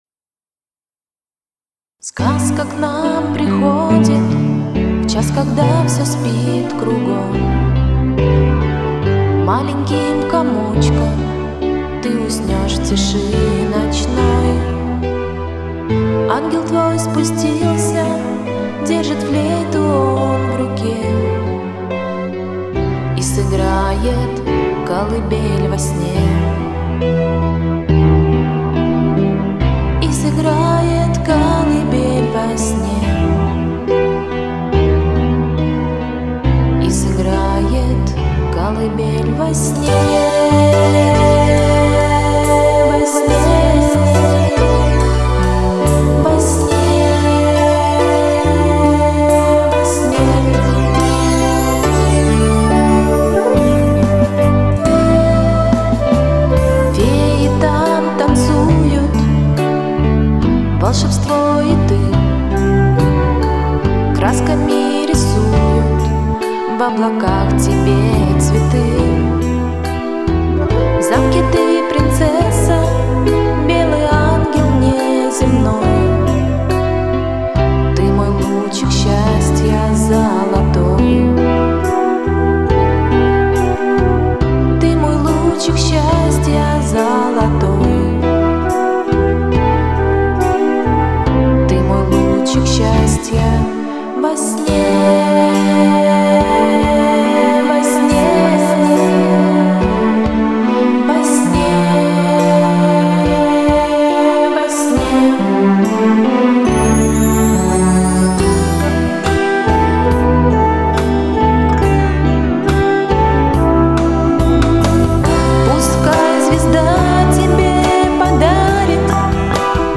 Тут она хотела типа песенку типа колыбельной своей дочке.